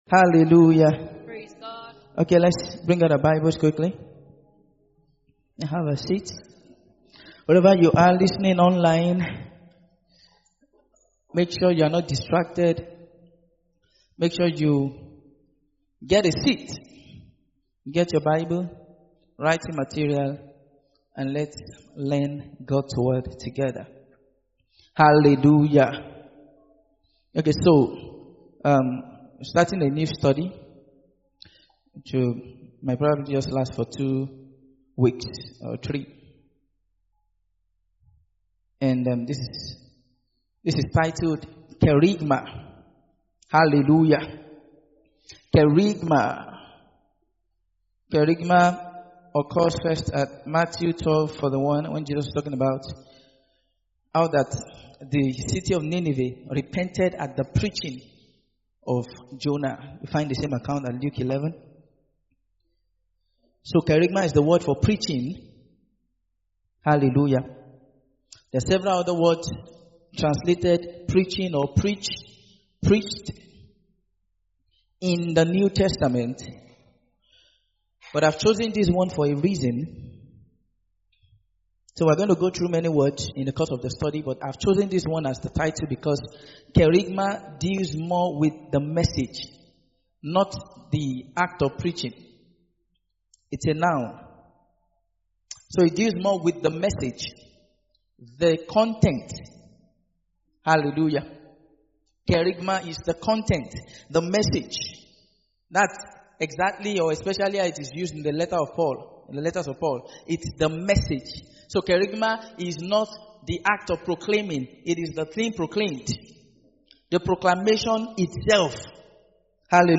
Kerygma (How the Gospel was Proclaimed) - Part 1 - TSK Church, Lagos
Sunday Sermons